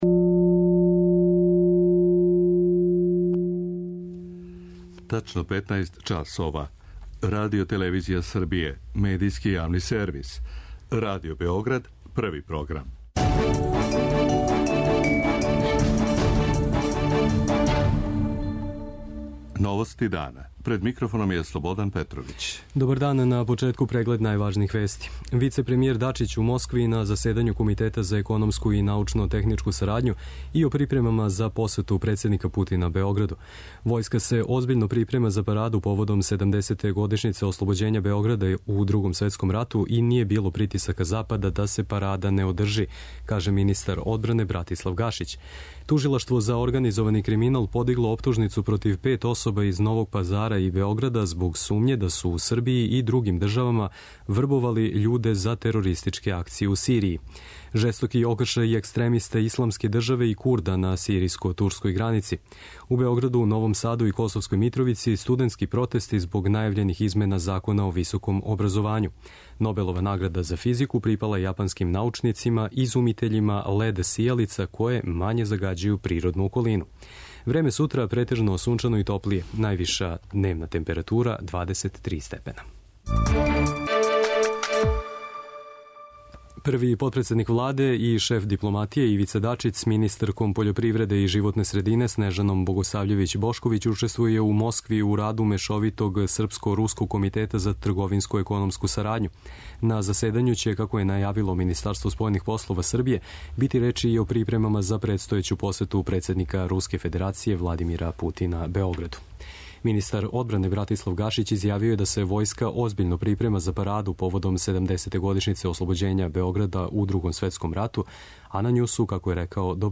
Новости дана